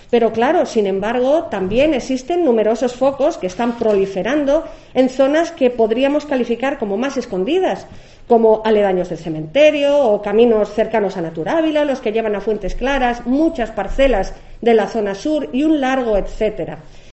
Sonsoles Sánchez-Reyes, portavoz PP. Vertederos incontrolados